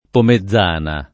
Pomezzana [ pome zz# na ]